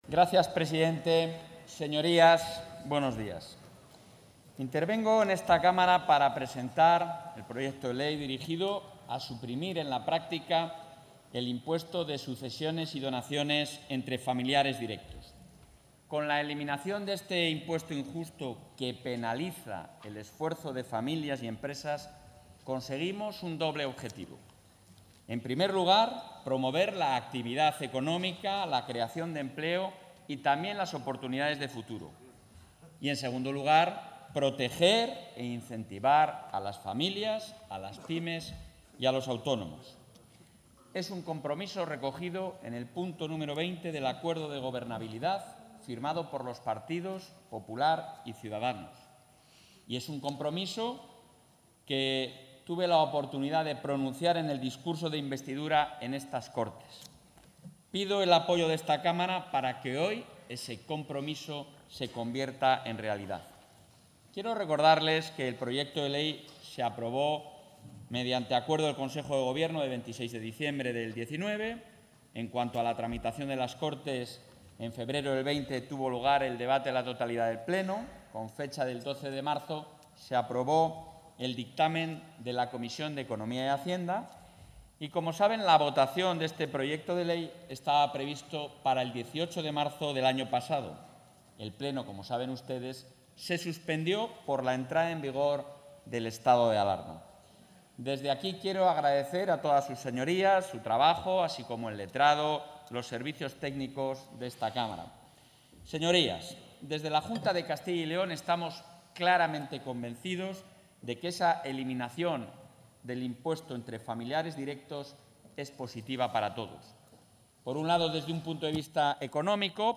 Intervención del presidente.
Las Cortes de Castilla y León han aprobado la modificación legislativa, que ha presentado el propio Alfonso Fernández Mañueco y que entrará en vigor con su publicación en el Boletín Oficial de Castilla y León. Además, el jefe del Ejecutivo autonómico ha aprovechado su intervención ante los parlamentarios para adelantar que ésta no será la última bajada de tributos que se presentará en esta legislatura.